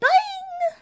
toadette_twirl_bounce.ogg